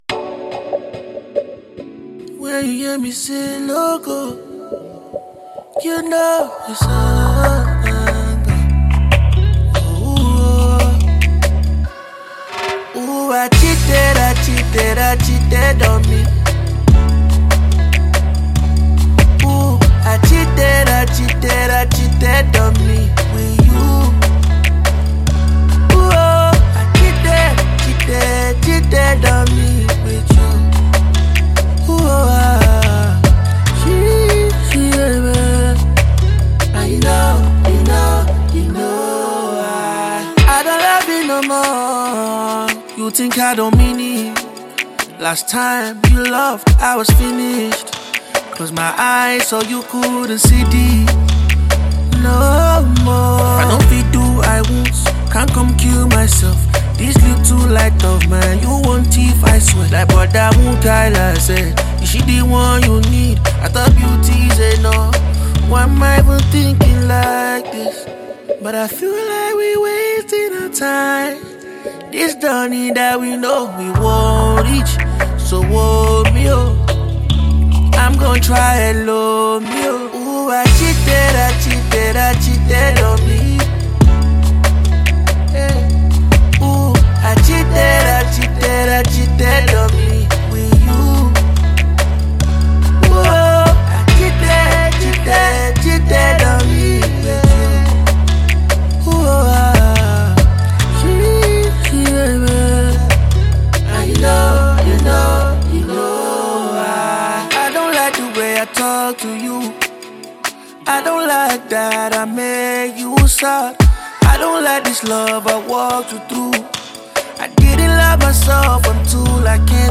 British-Nigerian talented singer